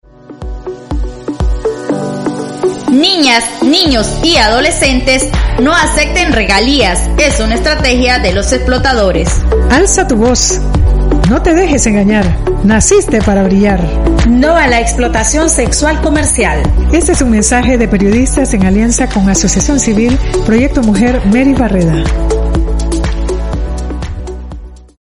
Les compartimos viñetas sobre la prevención de la violencia basada en género y la Explotación Sexual Comercial de niñas, niños y adolescentes elaboradas por mujeres periodistas y comunicadoras sociales integradas en los procesos de formación que desarrolla la organización.